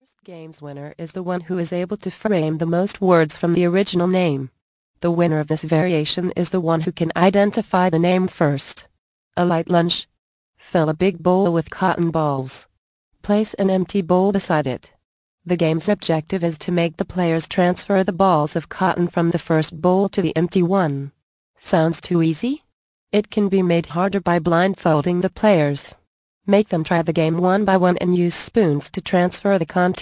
The Ultimate Baby Shower Guide mp3 audio book Vol. 3